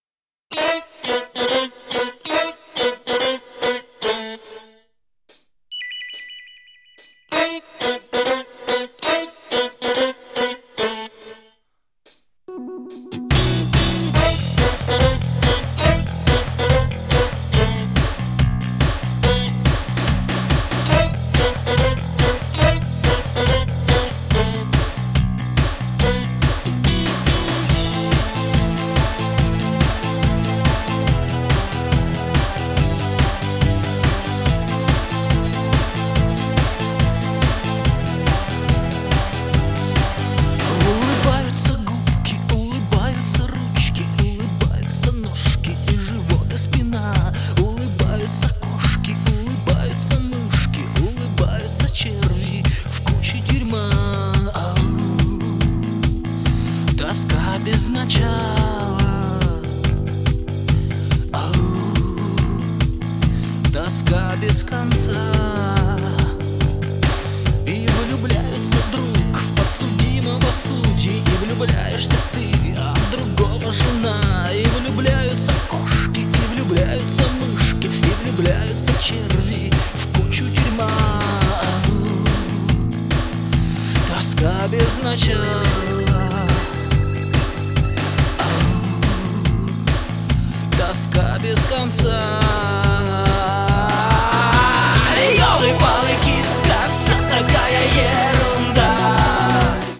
• Качество: 128, Stereo
Synth-punk
психоделический рок
post-punk